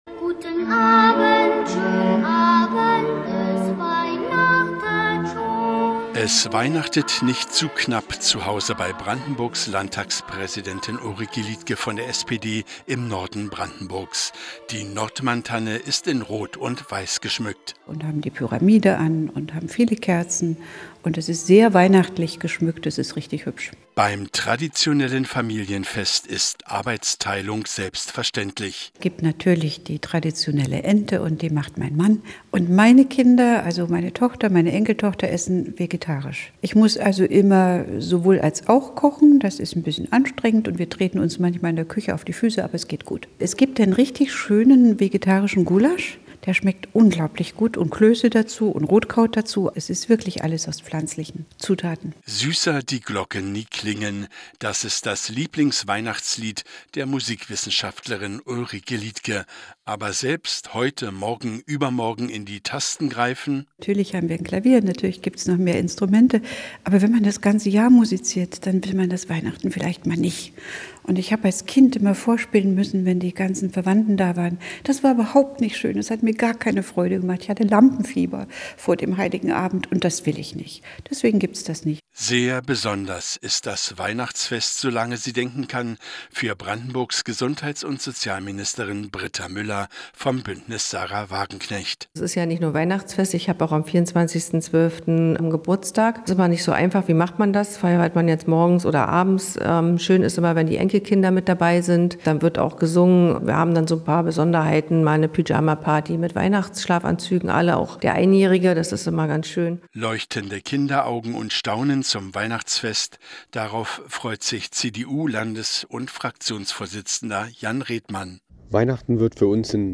hat sich in Potsdam umgehört